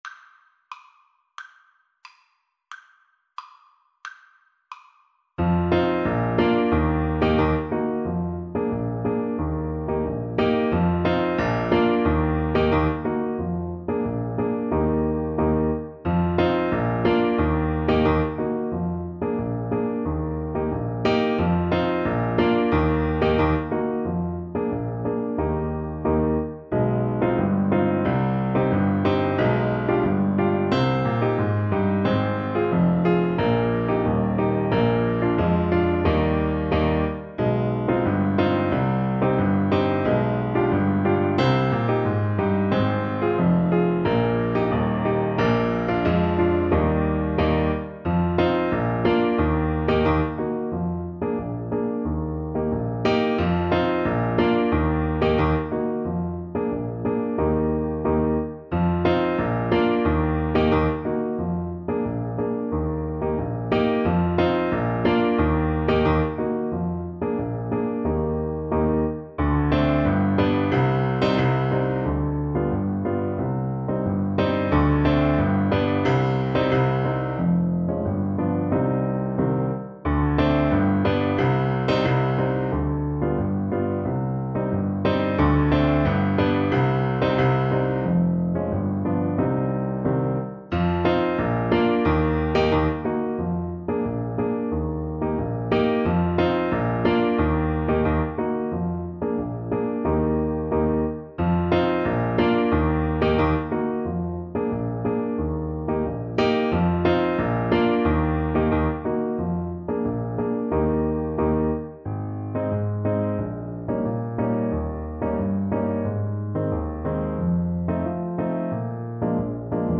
Allegro =c.90 (View more music marked Allegro)
2/4 (View more 2/4 Music)
Classical (View more Classical Clarinet Music)
Brazilian Choro for Clarinet